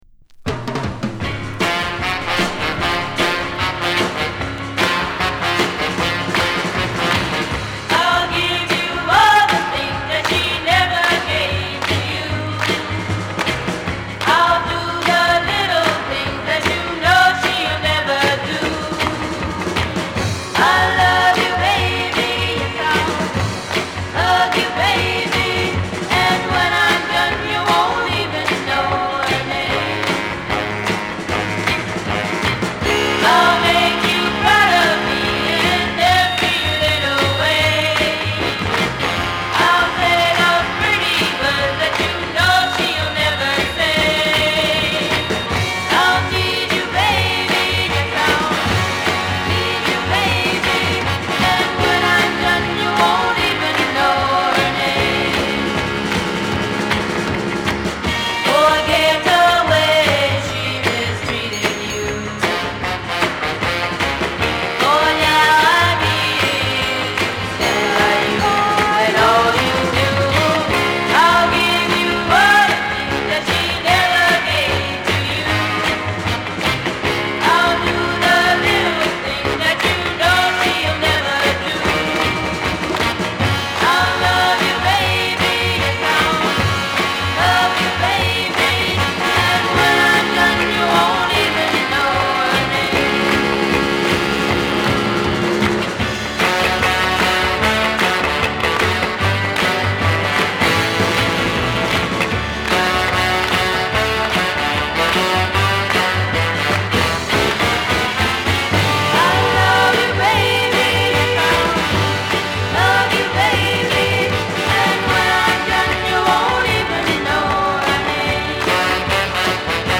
希望に満ちたヴォーカルに華々しいホーンを絡めたアップテンポ・ガールズ・サウンドが全面に響き渡る。